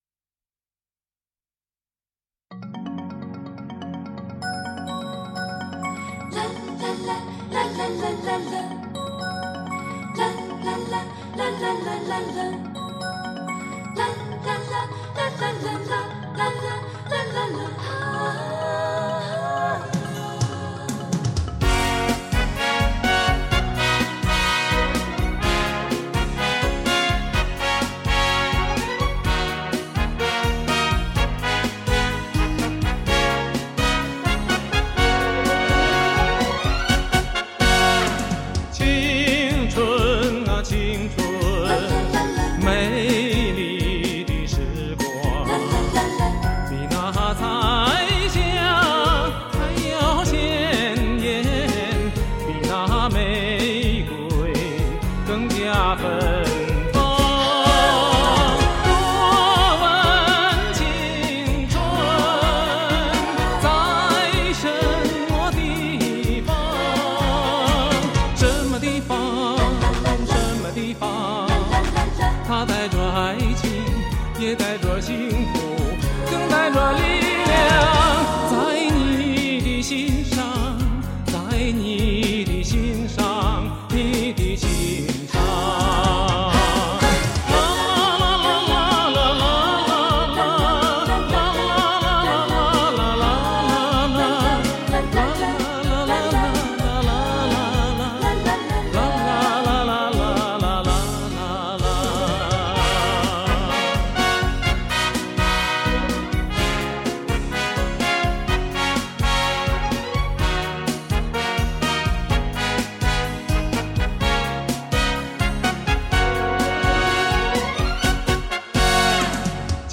中国大陆著名男高音歌唱家，中国国家一级演员。
音域宽广，音色甜美醇厚，将西洋发声和民族唱法融为一体，形成了独特的演唱风格。